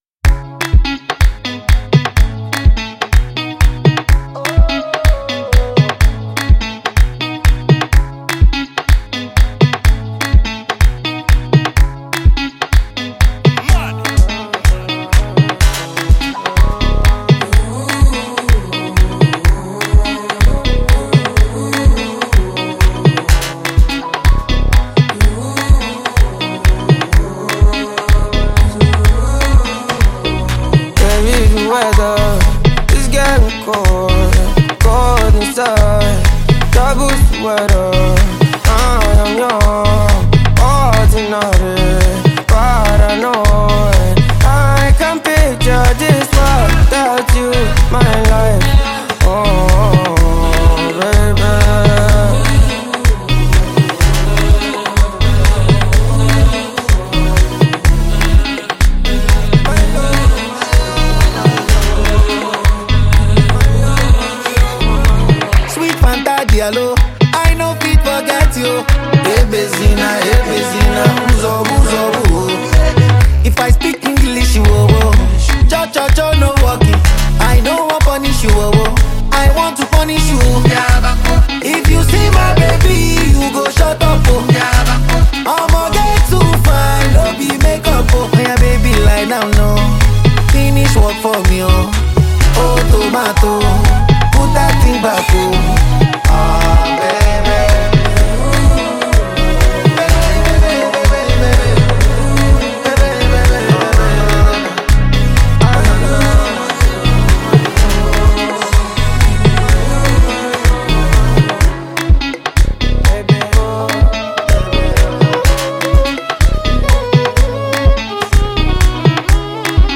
signature Afrobeats energy
soulful, melodic touch
bold vocal delivery
laid-back, introspective vibe